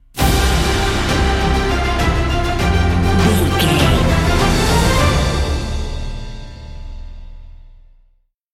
Epic / Action
Uplifting
Aeolian/Minor
powerful
brass
cello
drums
strings